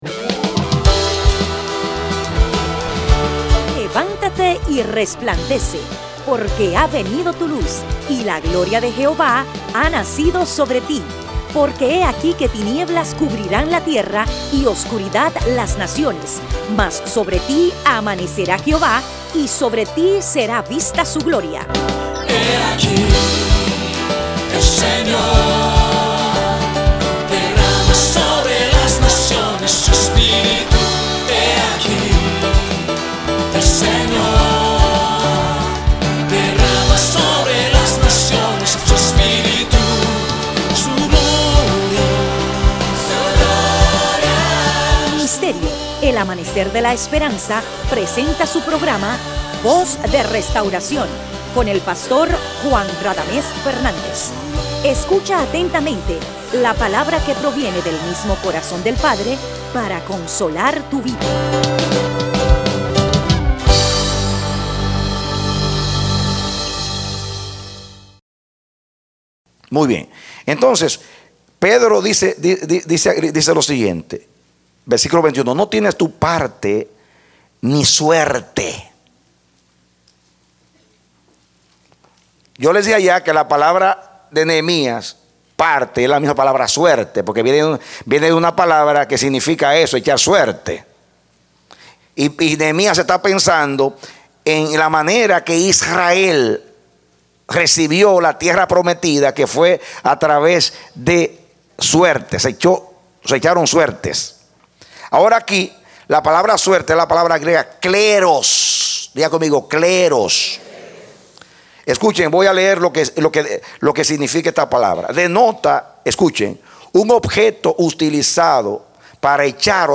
A mensaje from the serie "Mensajes." Parte B Predicado Febrero 24,2013